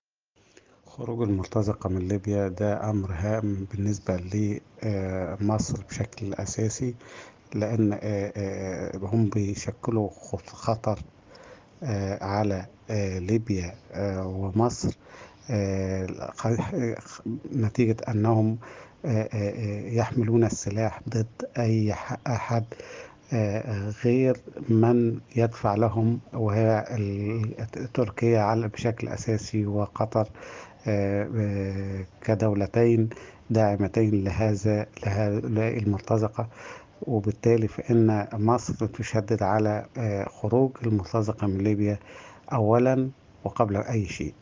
محلل سياسي وكاتب صحفي